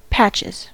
patches: Wikimedia Commons US English Pronunciations
En-us-patches.WAV